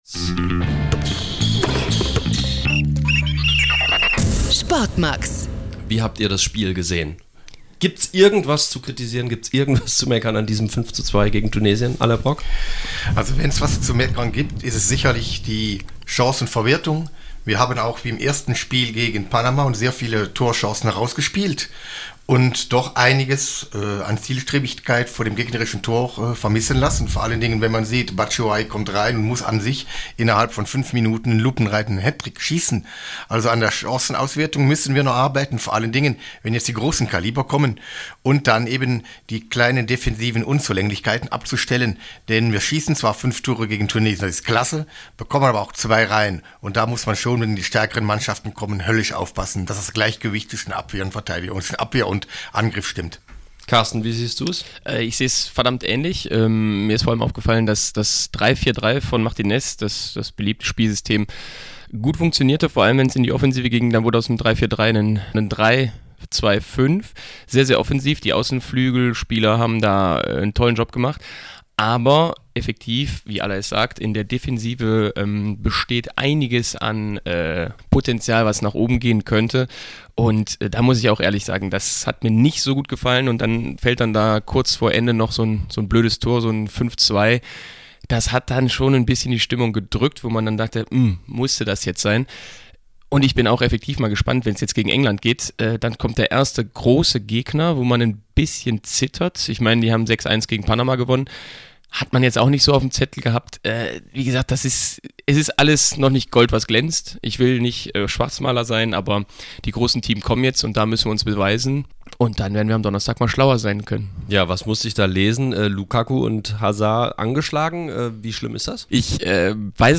WM-Talk